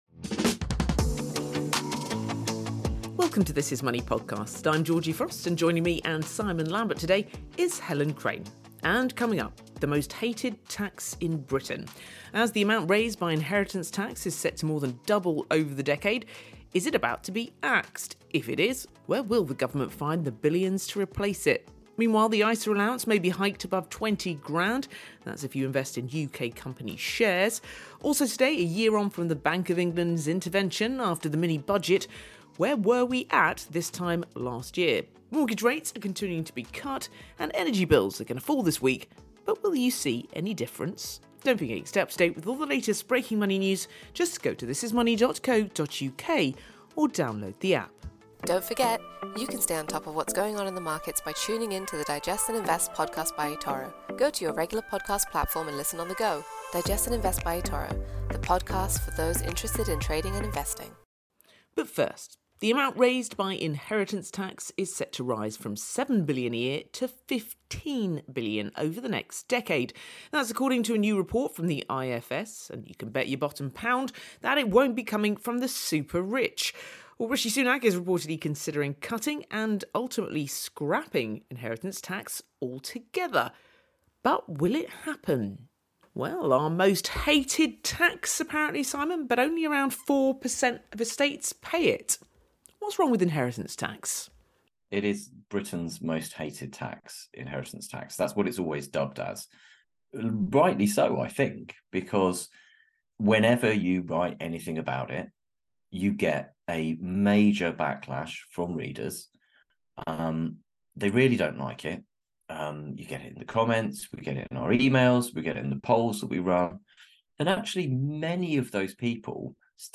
The team consider what puts people off stocks and shares Isas, whether the rules are too restrictive for the way we manage our money today, and whether encouraging people to pour money into a market which has had a bit of a tough time of late is a good idea.